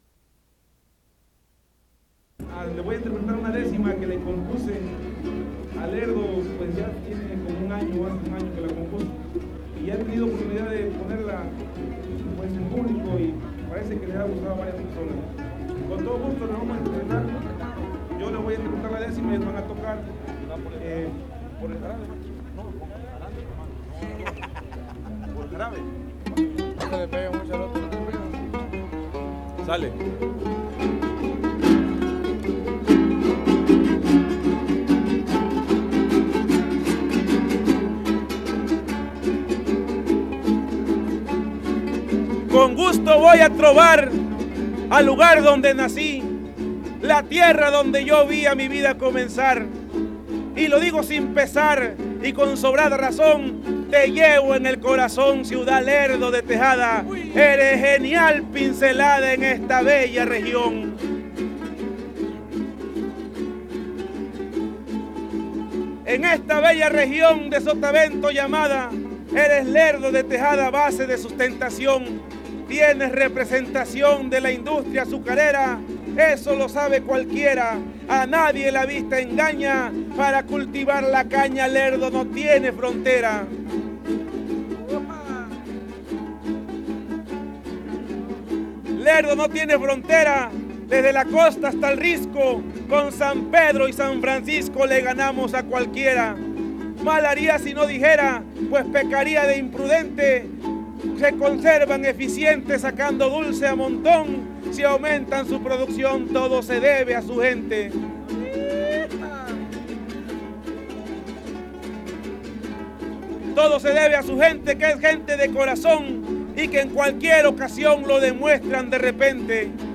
Fandango